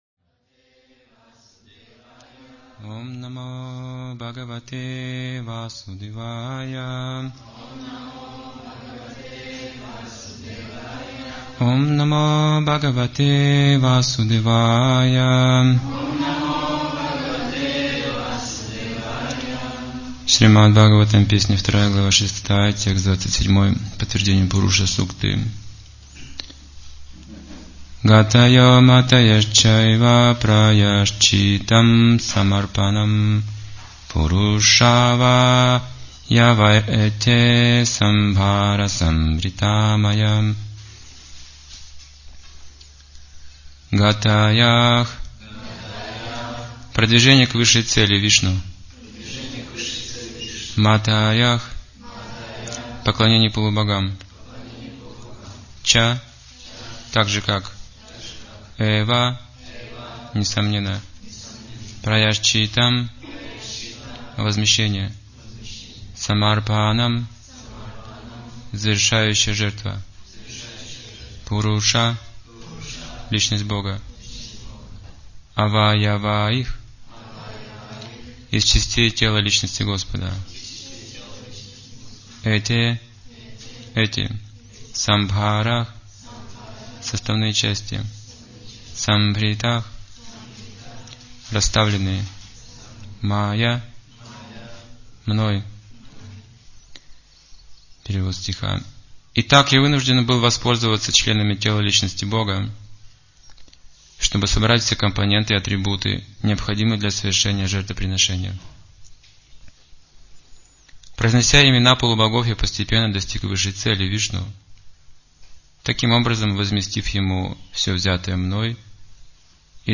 Темы, затронутые в лекции: Законы материального мира Ягья Совместные усилия Умонастроение Состовляющие ягьи Описание ягьи Ключ успеха Важность ягьи Практичность наш принцип